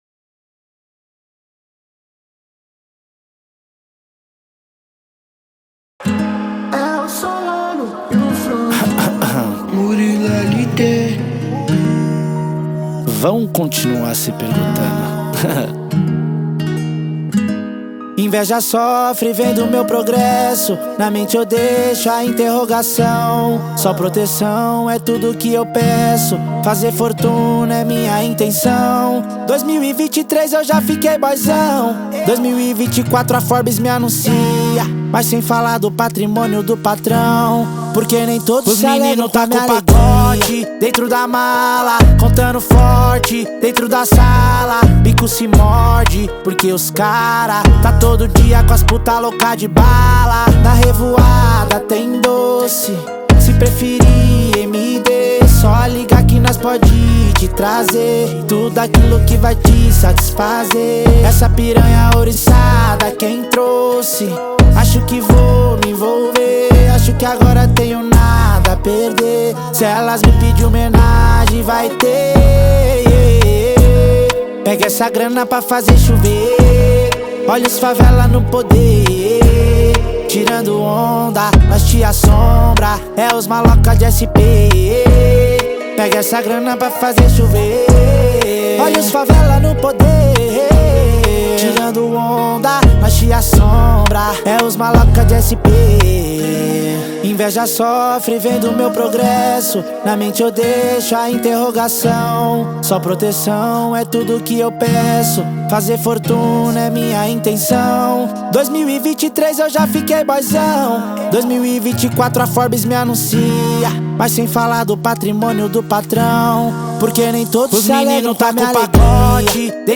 Baixar Funk